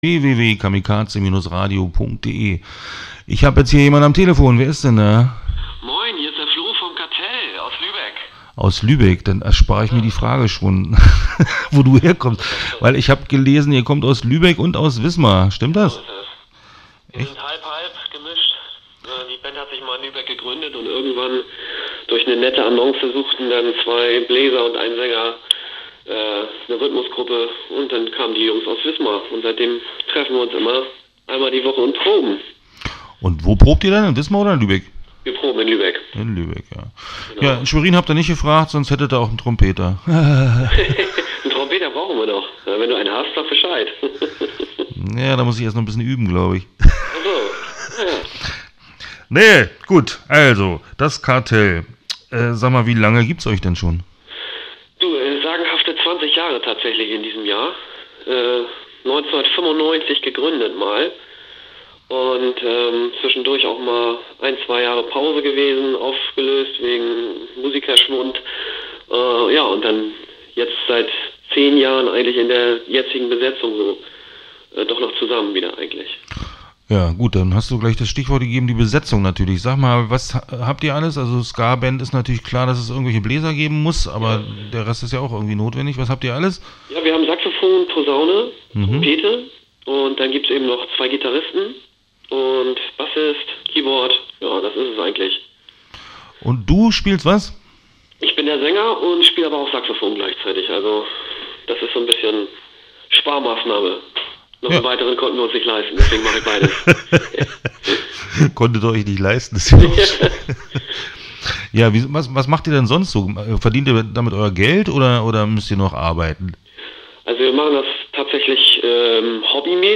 Start » Interviews » Daskartell